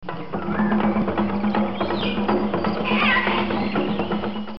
TAMBOR SELVA JUNGLE DRUM
Ambient sound effects
Tambor_selva_jungle_drum.mp3